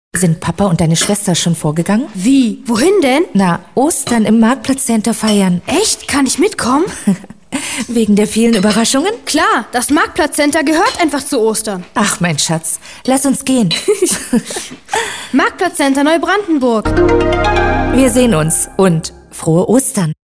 Radiospot Brandenburg | mütterlich | 0.20
radiospot.mp3